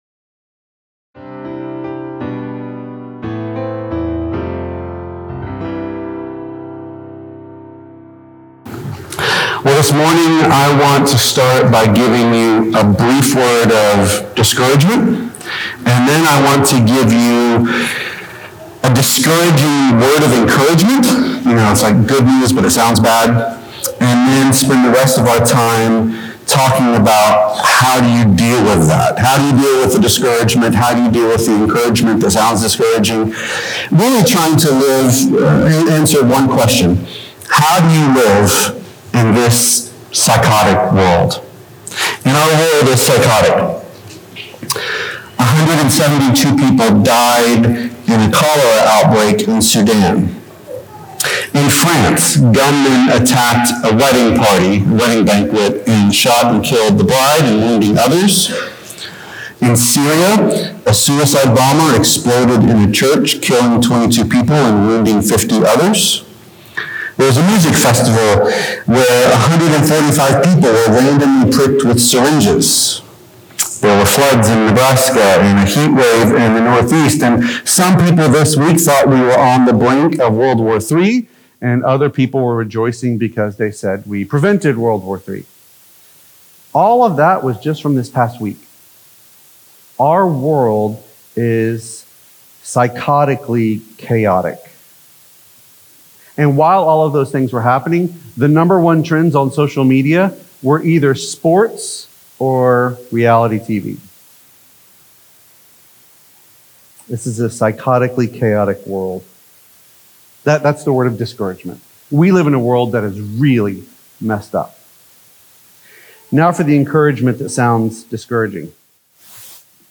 The sermon begins with a brief audio issue (first 90 seconds), but it’s quickly resolved, thanks for your patience!